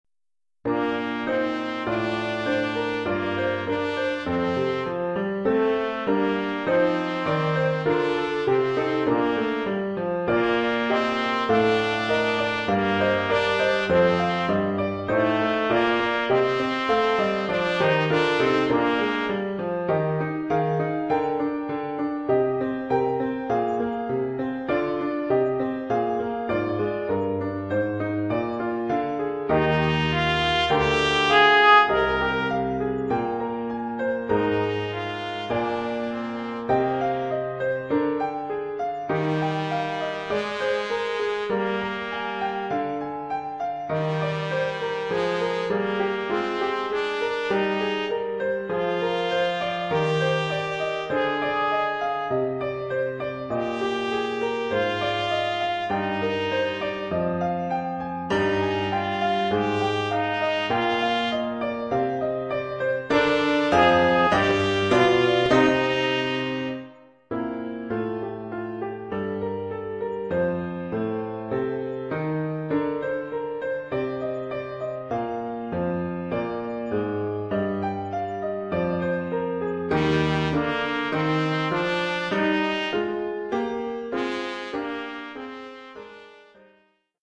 Oeuvre pour trompette sib ou ut
ou cornet ou bugle et piano.